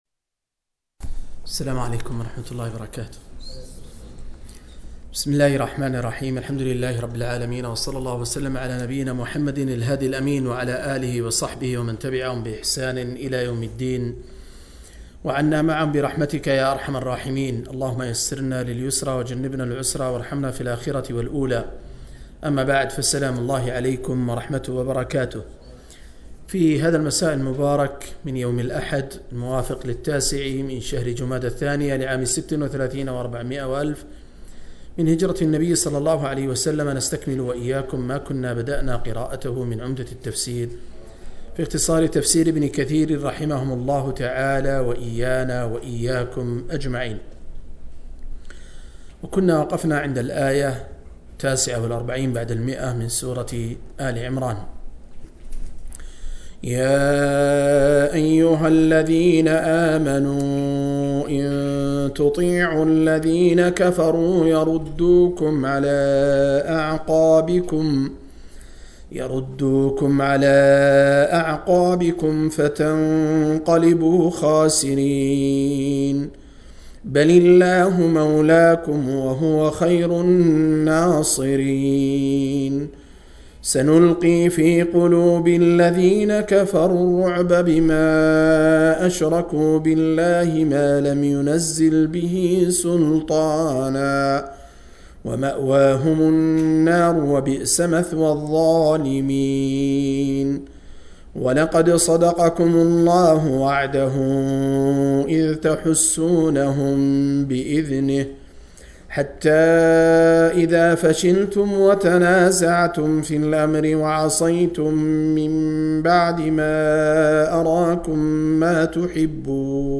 075- عمدة التفسير عن الحافظ ابن كثير رحمه الله للعلامة أحمد شاكر رحمه الله – قراءة وتعليق –